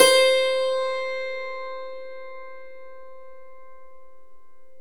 Index of /90_sSampleCDs/E-MU Formula 4000 Series Vol. 4 – Earth Tones/Default Folder/Hammer Dulcimer
DLCMR C3-R.wav